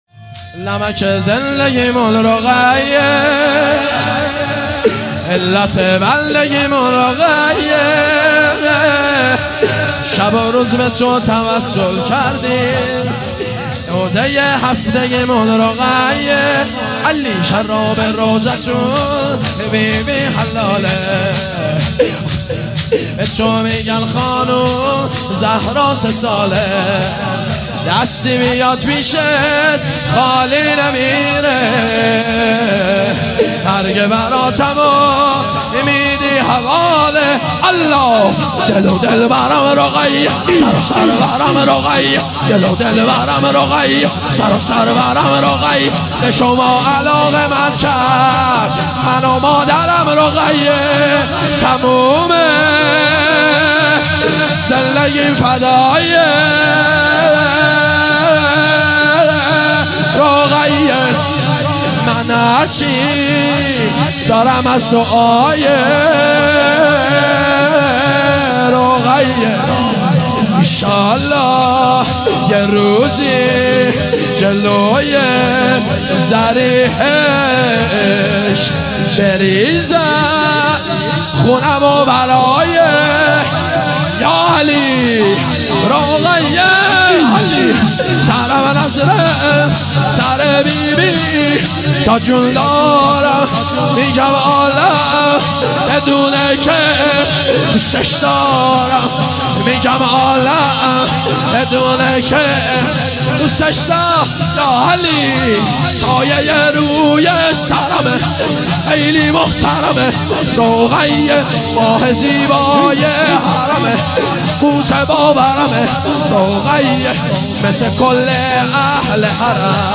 جلسه هفتگی هیئت جنت العباس (ع) کاشان